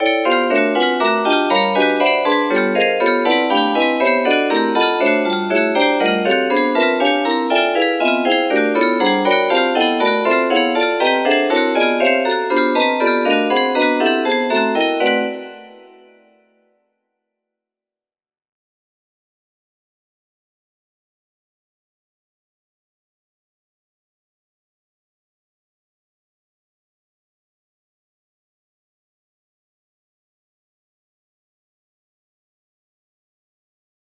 P6 music box melody